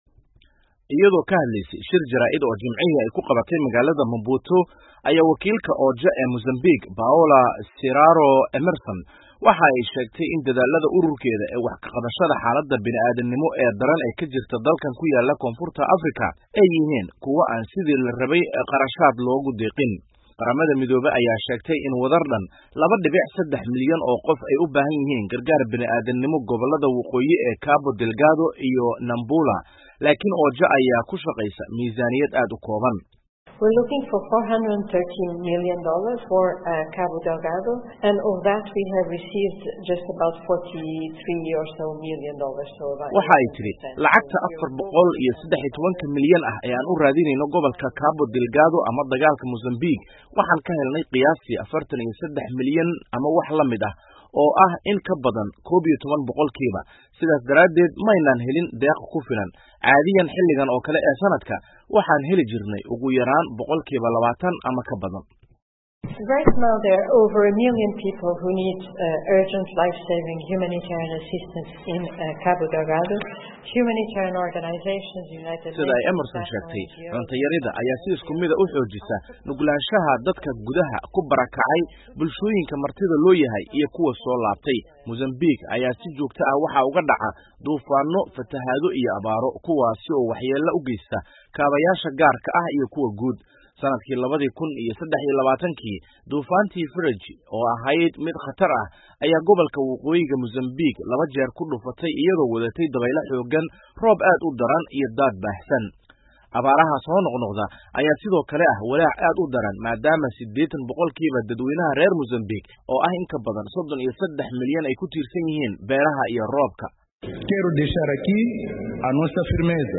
ka soo diray magaalada Maputo ee caasimadda Mozambique.